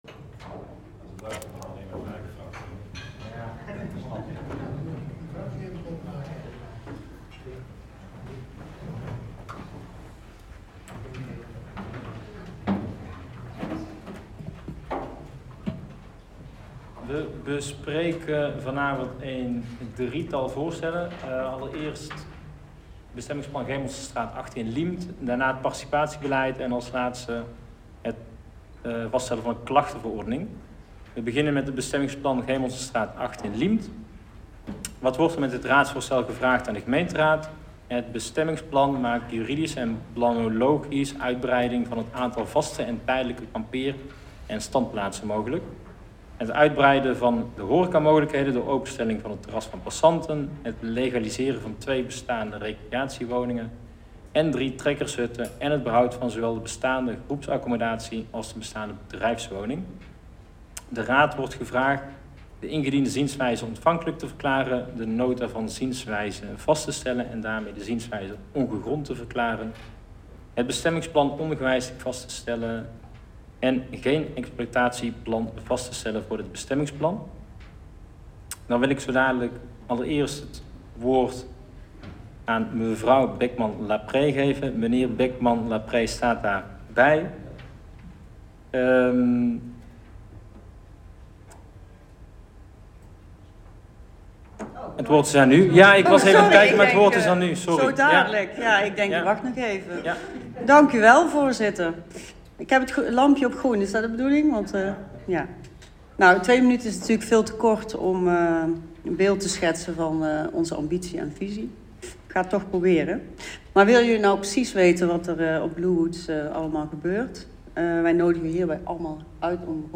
Agenda MijnGemeenteDichtbij - In-gespreksavond dinsdag 3 oktober 2023 19:30 - 22:30 - iBabs Publieksportaal